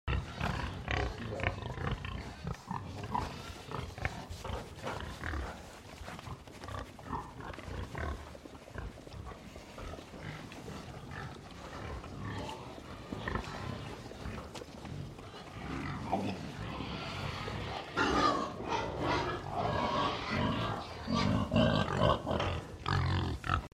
دانلود صدای مزرعه 19 از ساعد نیوز با لینک مستقیم و کیفیت بالا
جلوه های صوتی
برچسب: دانلود آهنگ های افکت صوتی طبیعت و محیط دانلود آلبوم صدای مزرعه روستایی از افکت صوتی طبیعت و محیط